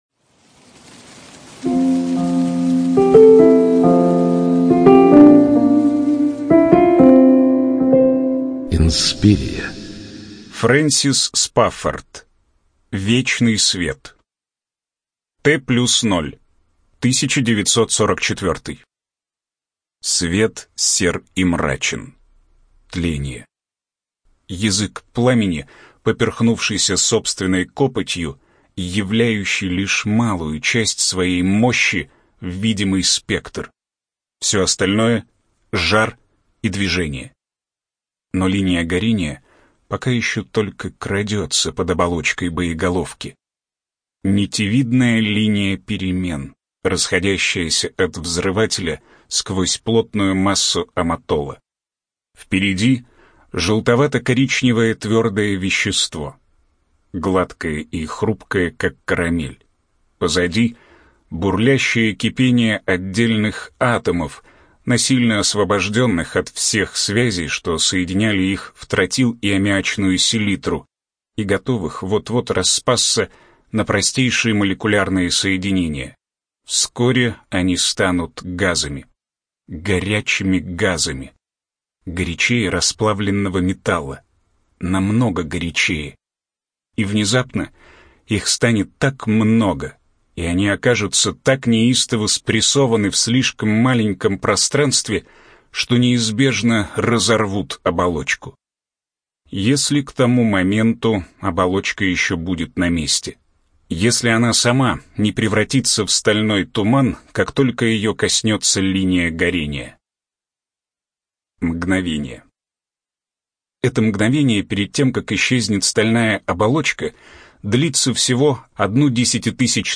Студия звукозаписиInspiria